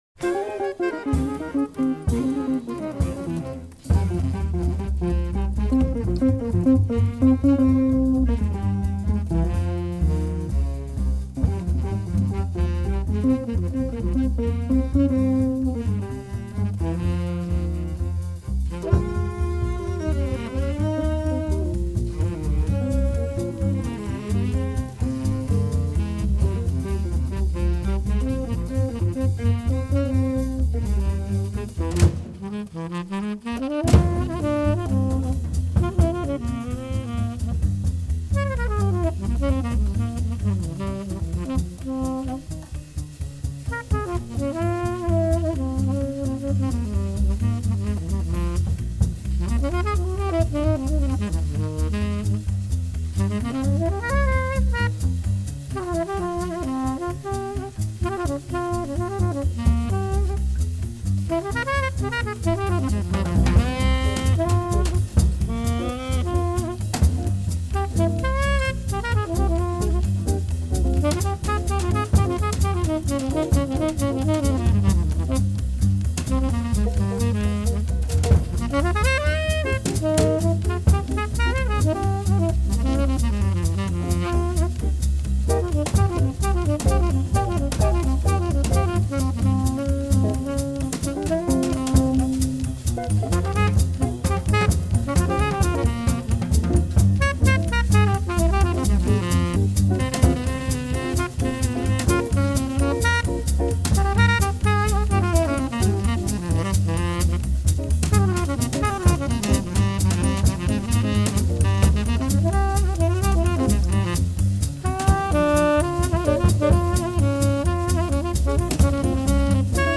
West Coast Cool School
guitar
flute, clarinet, alto and tenor sax
cello